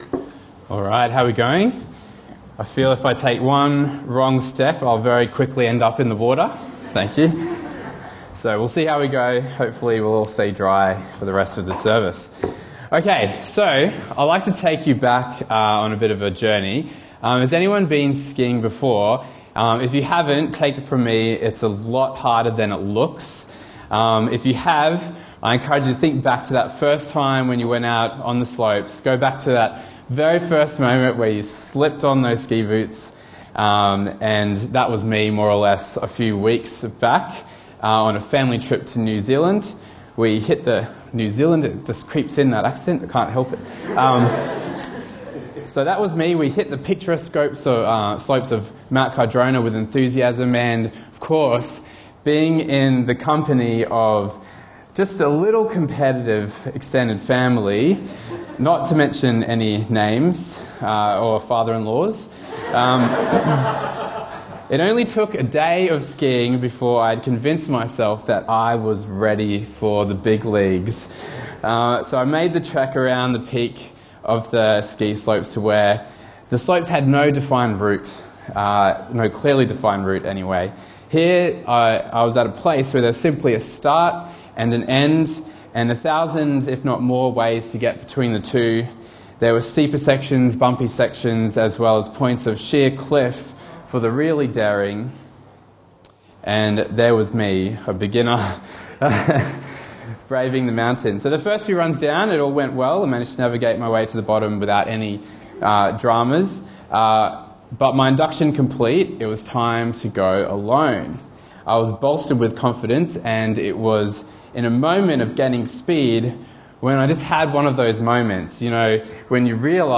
Bible Talks Bible Reading: Luke 15:1-7